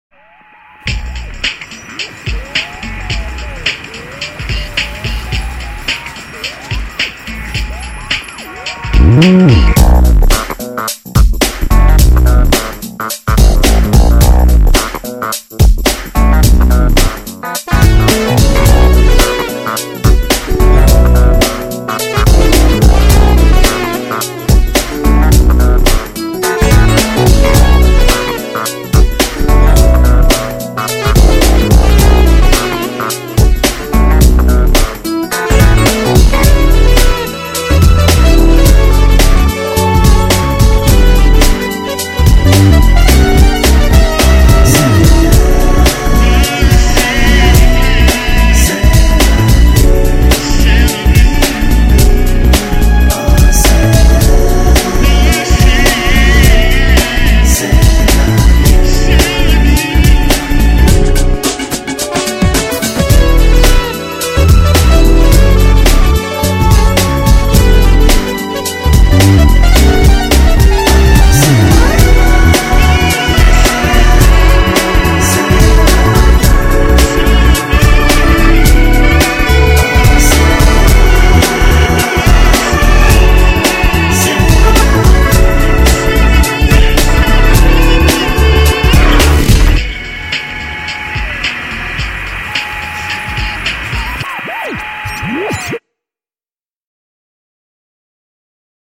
일렉트로니카를 살짝 얹은 퓨젼느낌이 나는 곡입니다.